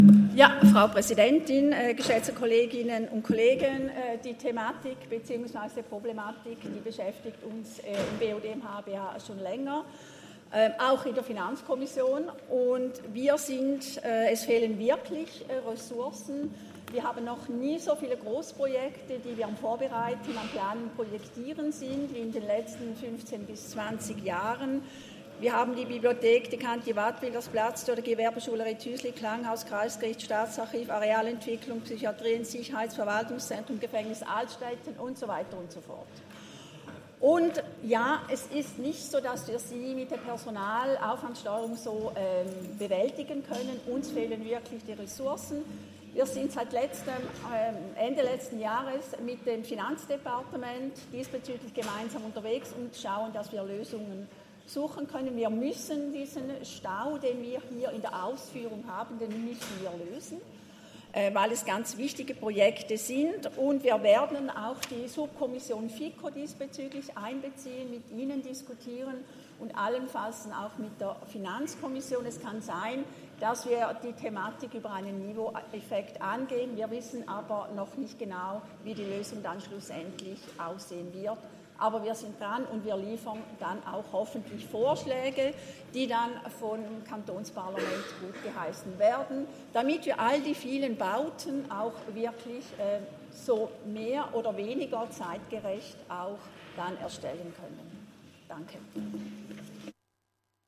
Session des Kantonsrates vom 12. bis 14. Juni 2023, Sommersession
12.6.2023Wortmeldung